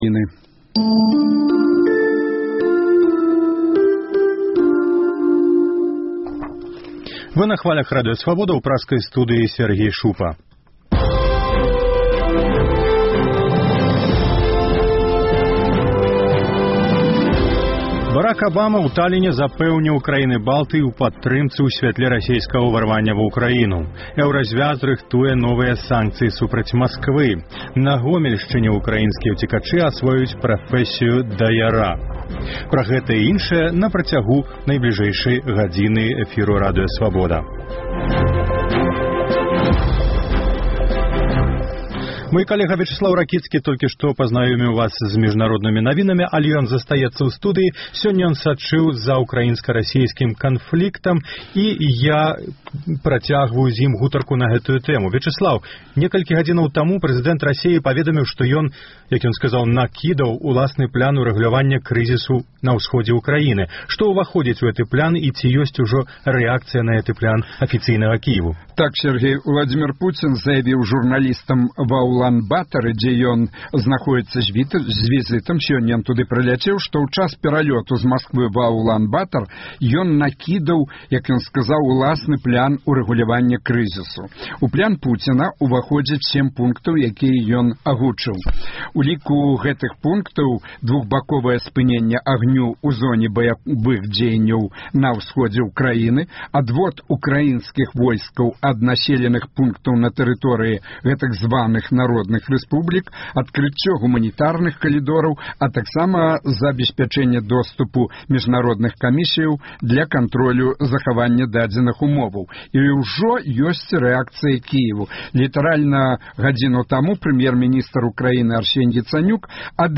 Паведамленьні нашых карэспандэнтаў, госьці ў жывым эфіры, званкі слухачоў, апытаньні ў гарадах і мястэчках Беларусі.